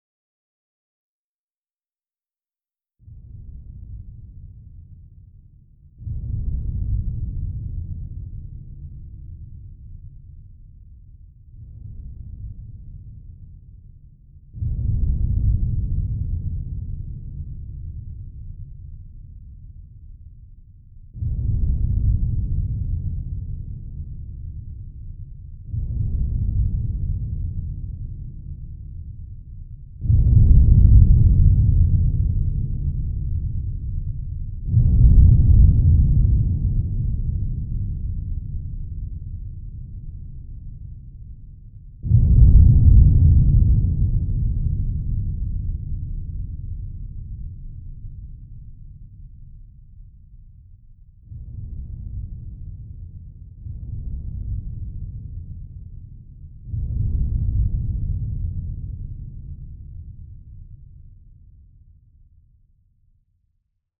ca37fcf28b Divergent / mods / Soundscape Overhaul / gamedata / sounds / ambient / soundscape / underground / under_25.ogg 1.0 MiB (Stored with Git LFS) Raw History